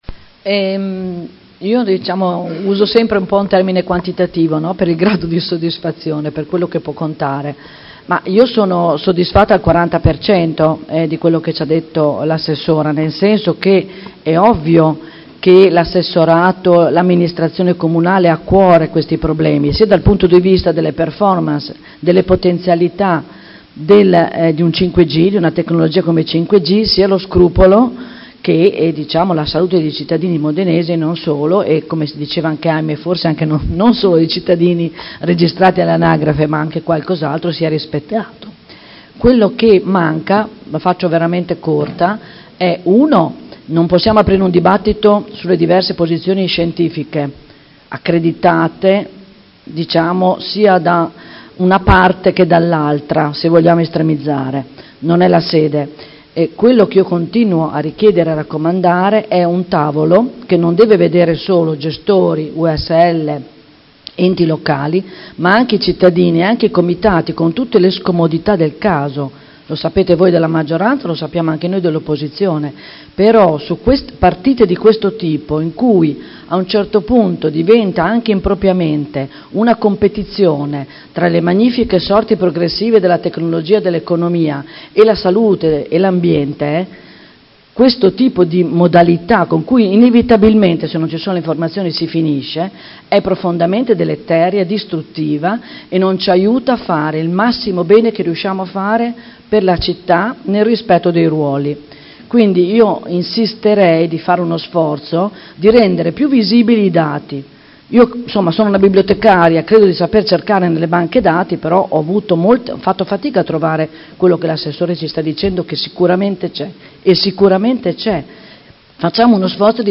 Enrica Manenti — Sito Audio Consiglio Comunale
Seduta del 19/12/2019 Replica a risposta Assessora Ludovica Ferrari.